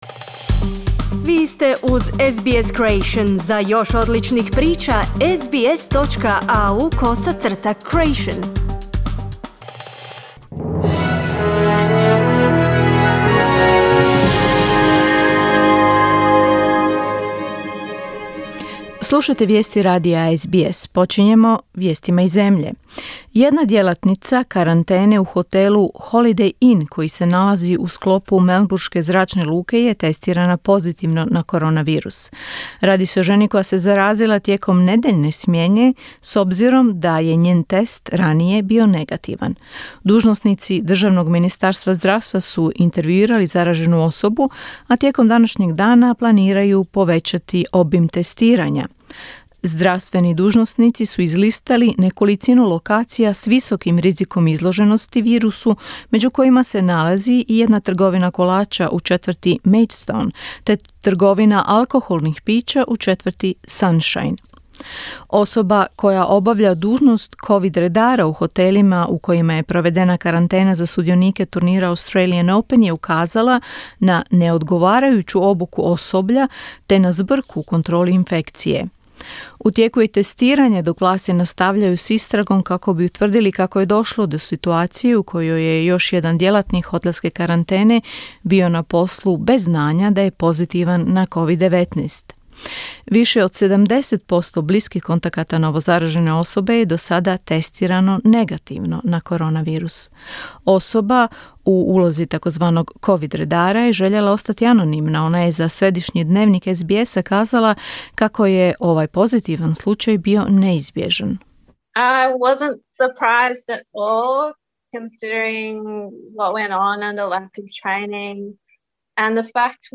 Vijesti radija SBS na hrvatskom jeziku